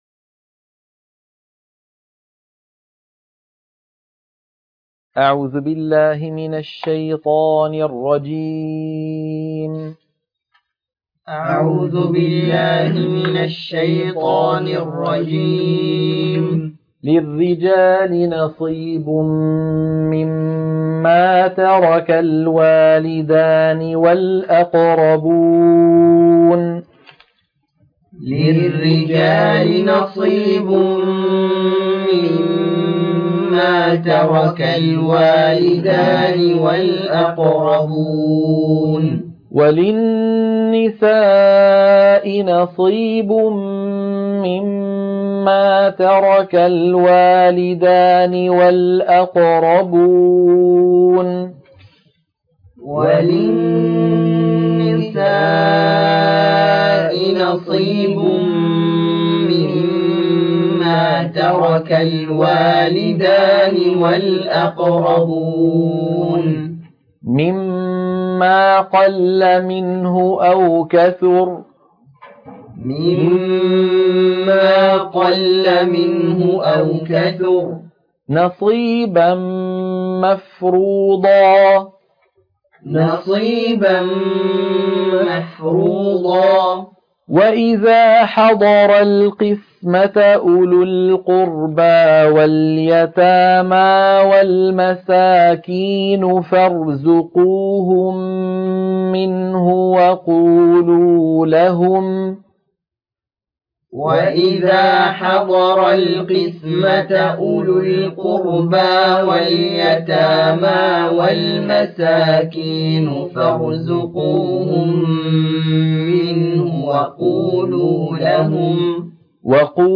عنوان المادة تلقين سورة النساء - الصفحة 78 التلاوة المنهجية